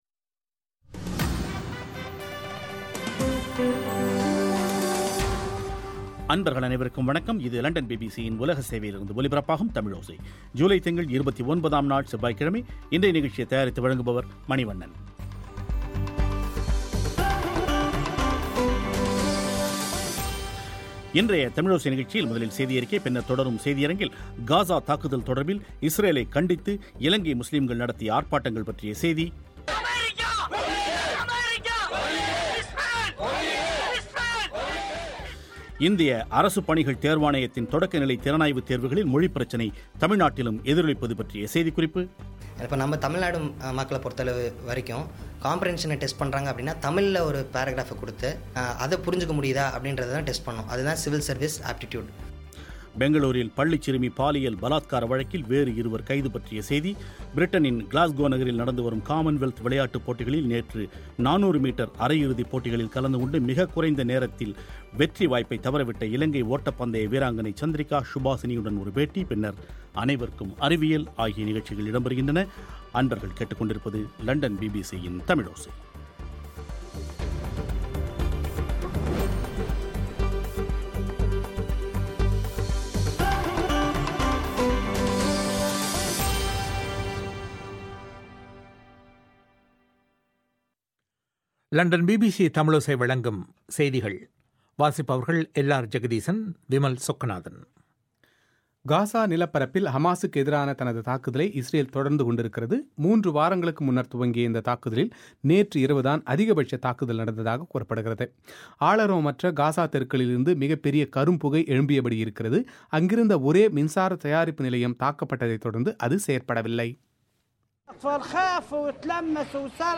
ஒரு பேட்டி